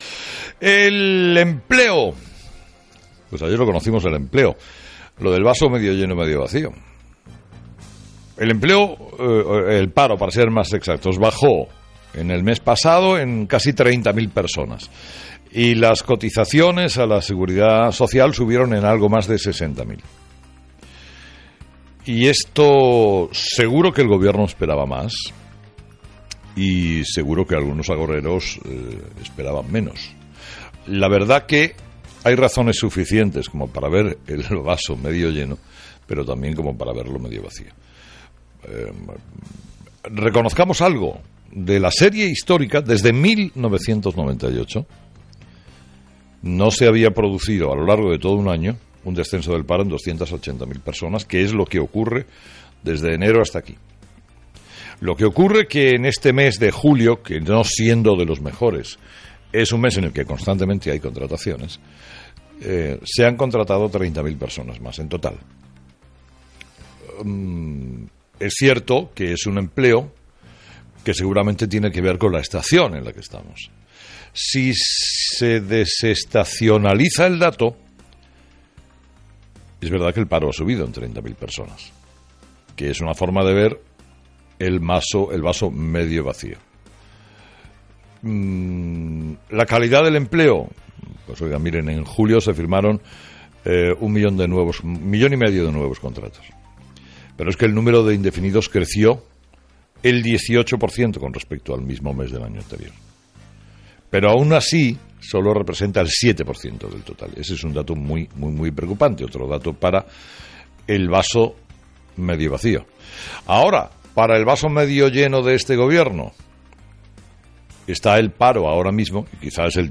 Herrera habla en su editorial de la cifra publicada ayer sobre el paro, que ha bajado en casi 30.000 personas y las cotizaciones subieron en mas de 60.000.